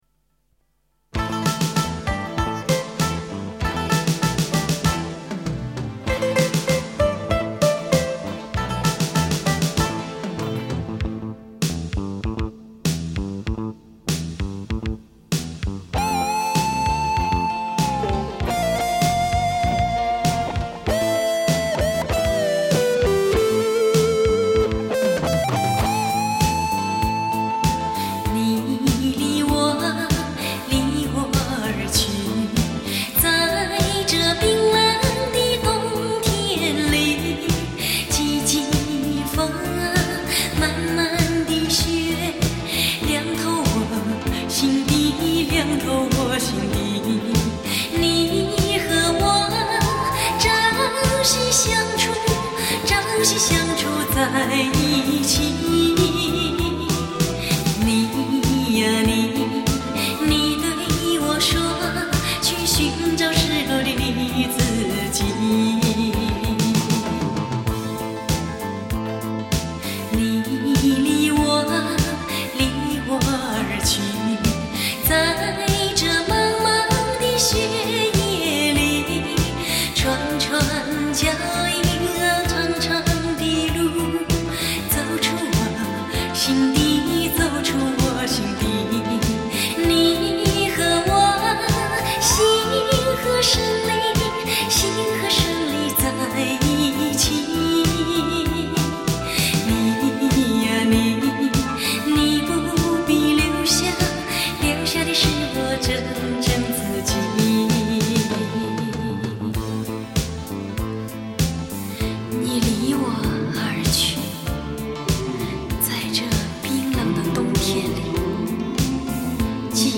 在音乐的海洋里，甜美的旋律总能传递出情感的力量。